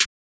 juugsnare1.wav